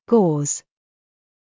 gauze はイギリス英語とアメリカ英語で発音が少し異なります。
【イギリス英語】gauze /gɔːz/
gauze-UK.mp3